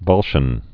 (vŏlshən, vôlskē-ən)